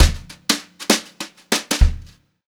200JZFILL2-R.wav